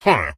Minecraft Version Minecraft Version 1.21.4 Latest Release | Latest Snapshot 1.21.4 / assets / minecraft / sounds / mob / evocation_illager / idle2.ogg Compare With Compare With Latest Release | Latest Snapshot